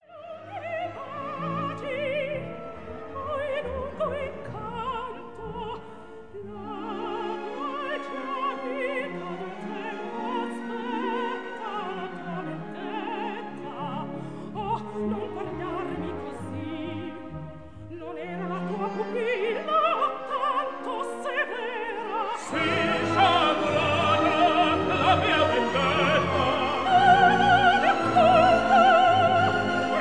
1958 stereo recording